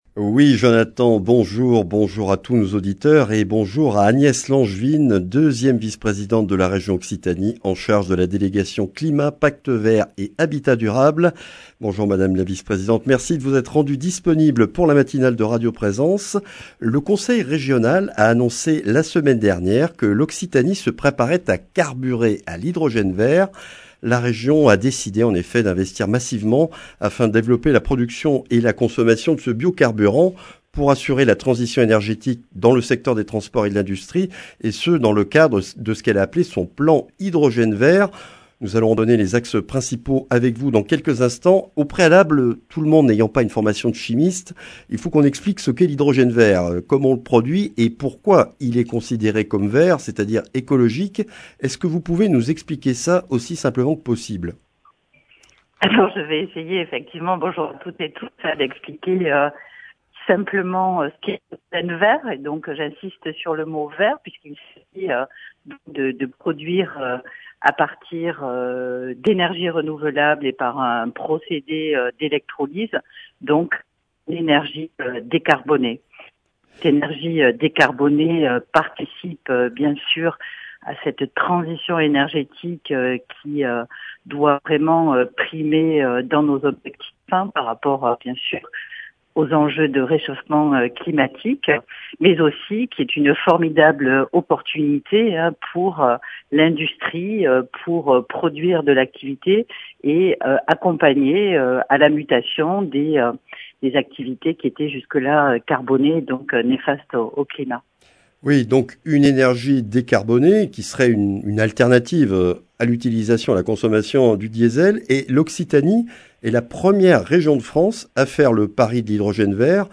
La Région Occitanie fait le pari de l’hydrogène vert en investissant 150 millions d’euros d’ici 2030 pour la production et l’utilisation de cette énergie décarbonée sur le territoire régional. Agnès Langevine, 2e vice-présidente de Région, déléguée au Climat, au Pacte vert et à l’Habitat durable, nous présente les grandes lignes et le déploiement du Plan Hydrogène vert en Occitanie.